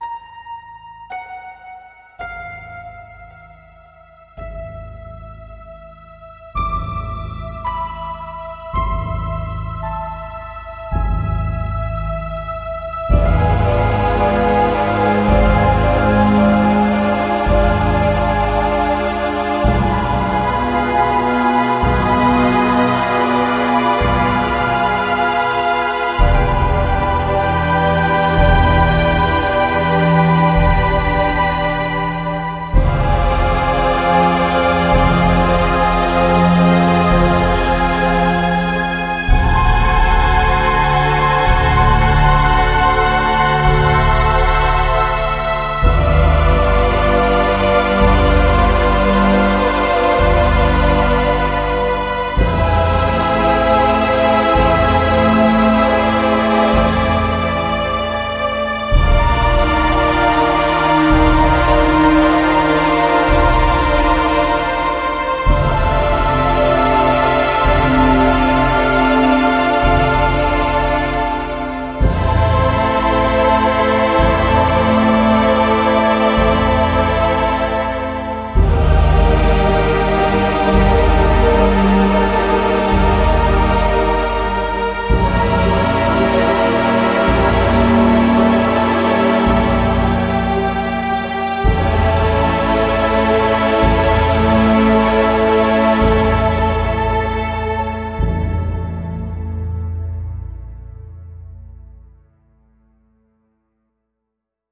Mystical theme